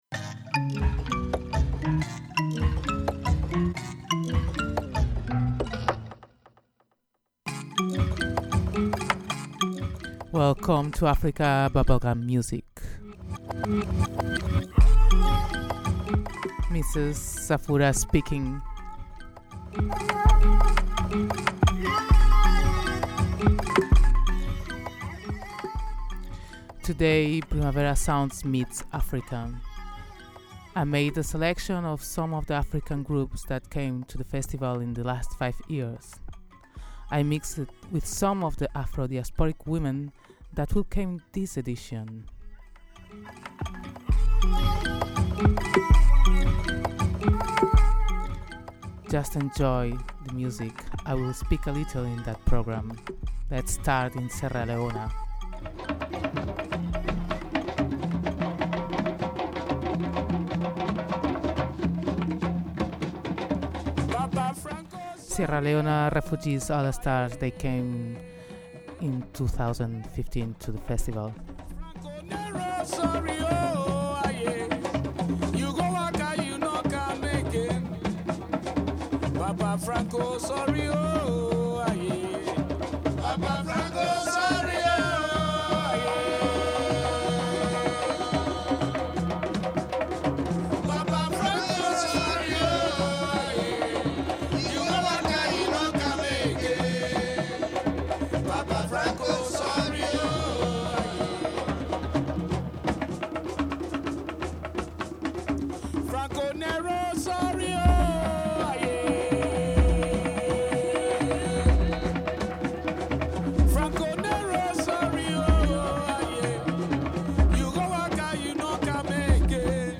Today we gonna listen to de real bubblegum sound